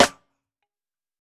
TC3Snare18.wav